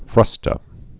(frŭstə)